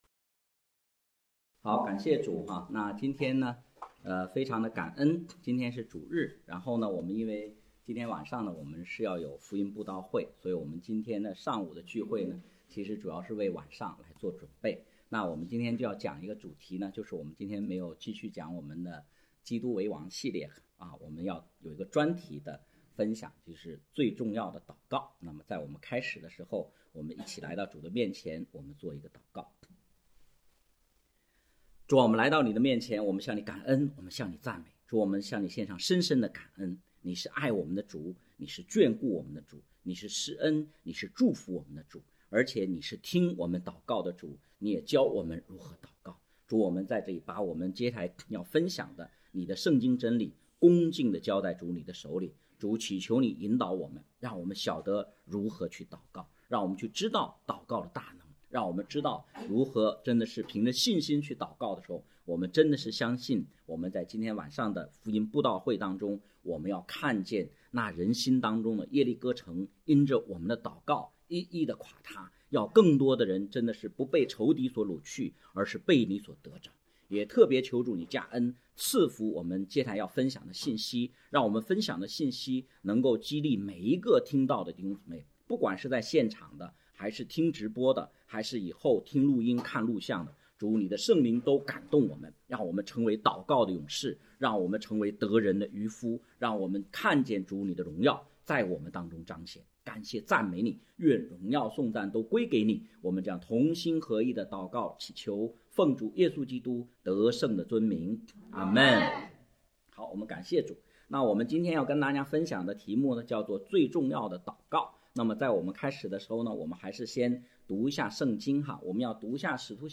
12月22日的主日，我们要为即将来临的圣诞布道会做预备，我们要做一篇特别的祷告专题主讲，盼望这篇讲道能鼓励大家为福音广传、福音扎根切切祷告：